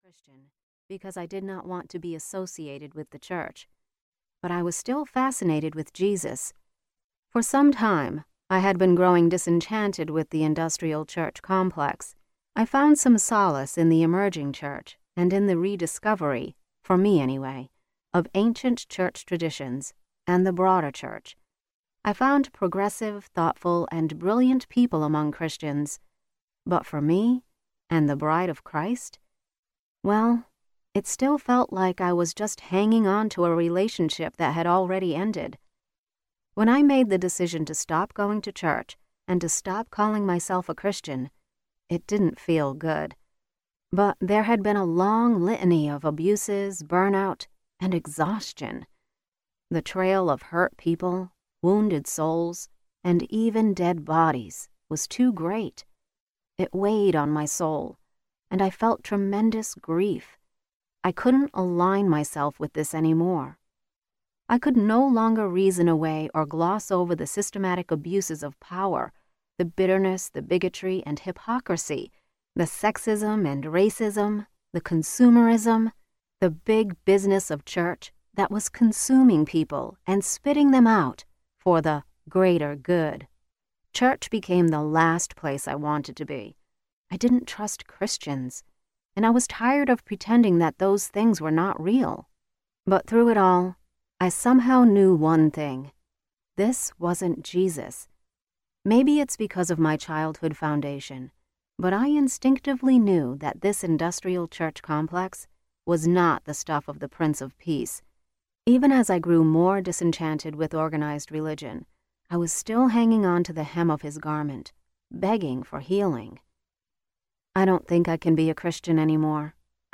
Out of Sorts: Making Peace with an Evolving Faith Audiobook
Narrator
7.55 Hrs. – Unabridged